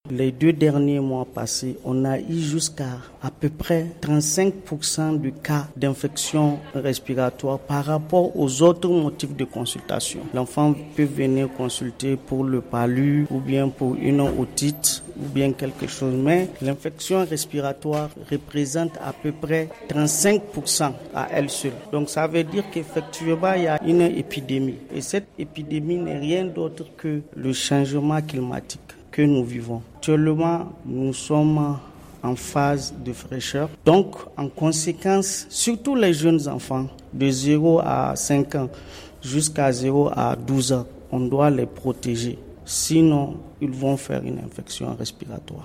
interviewé